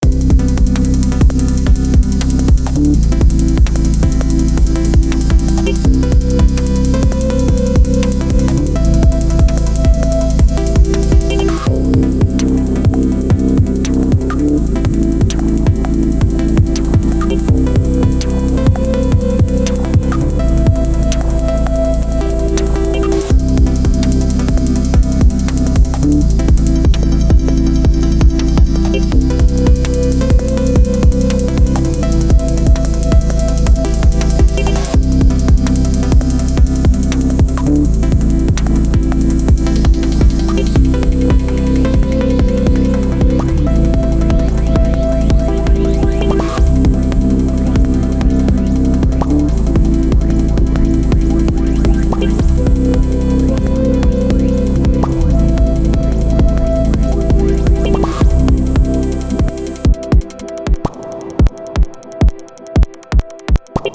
Digitone II is an electronica box